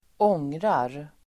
Uttal: [²'ång:rar]